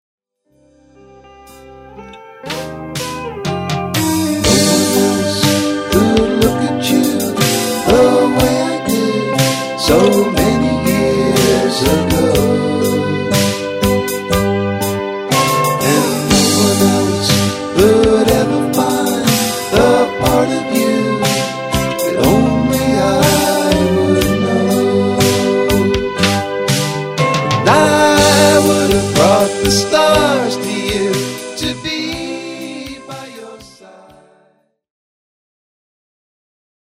USING AN ANALOG 8-TRACK.
THESE TUNES HAVE A RETRO FLARE TO THEM.
IF YOU'RE A FAN OF 60'S AND 70'S POP ROCK,